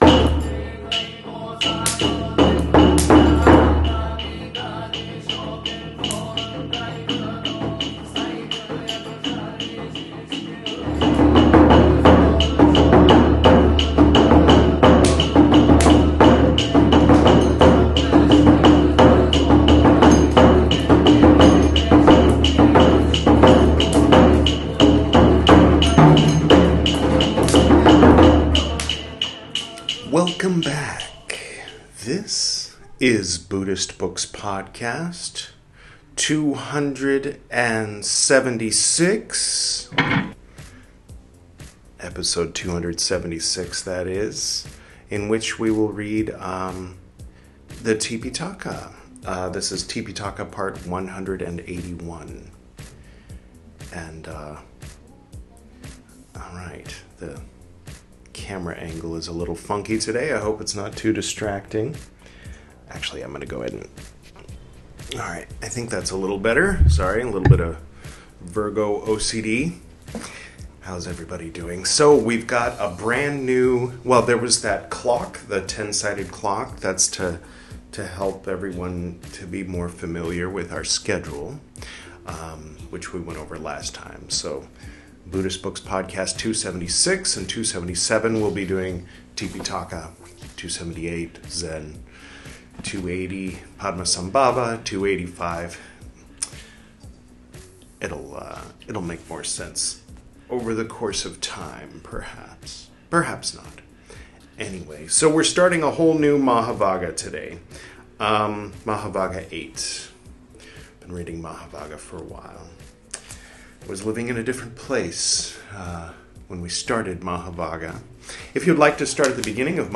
This is Part 181 of my recital of the 'Tipiṭaka,' the 'Three Baskets' of pre-sectarian Buddhism, as translated into English from the original Pali Language. In this episode, we'll begin reading 'Mahāvagga VIII,' from the 'Vinaya Piṭaka,' the first of the three 'Piṭaka,' or 'Baskets.'